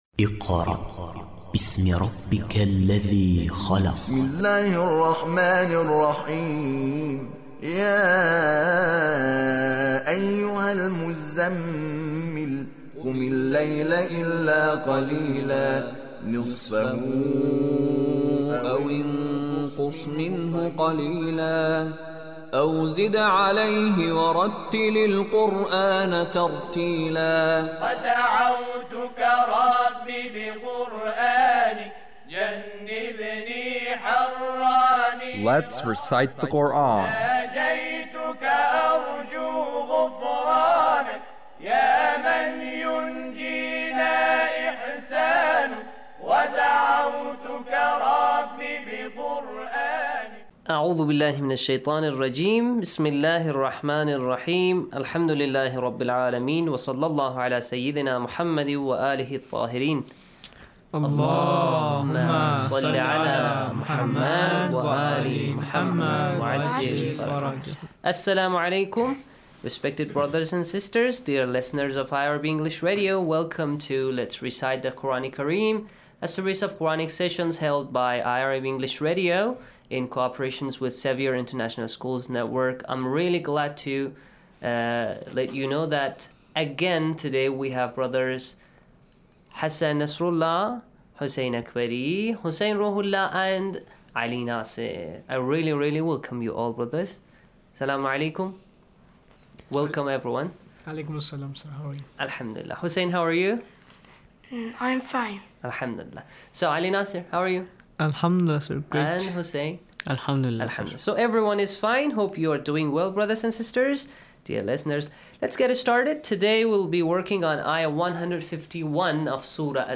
How to read Quran Sura al-Anaam verse 151